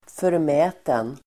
Ladda ner uttalet
Uttal: [förm'ä:ten]